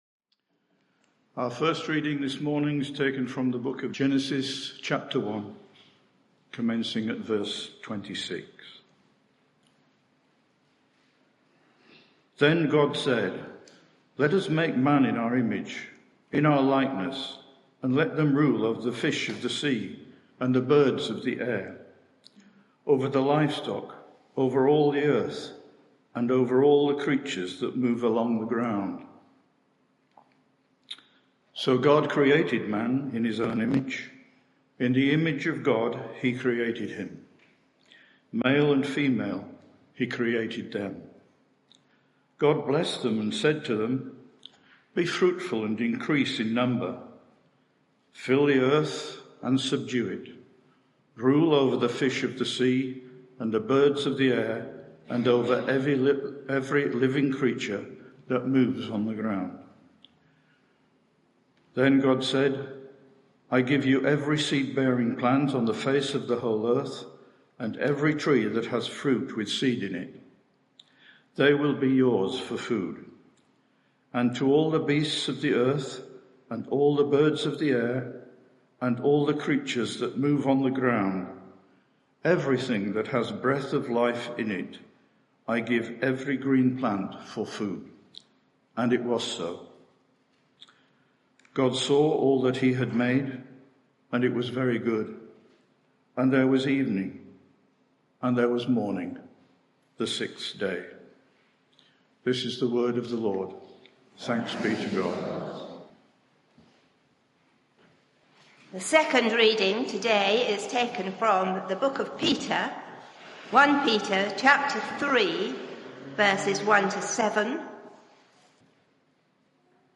Media for 11am Service on Sun 11th Jun 2023 11:00 Speaker
Sermon